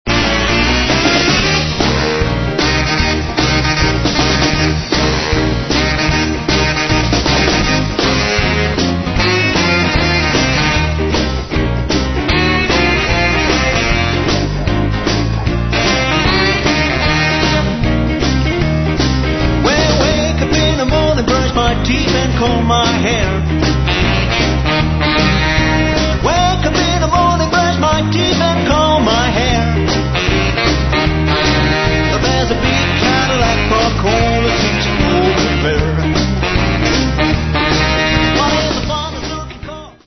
17 Titres country et Rock n’ roll